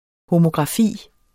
Udtale [ homogʁɑˈfiˀ ]